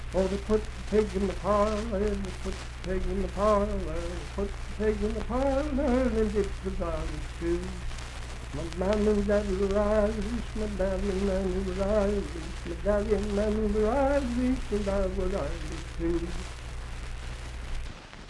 Unaccompanied vocal music
in Mount Storm, W.V.
Ethnic Songs, Dance, Game, and Party Songs
Voice (sung)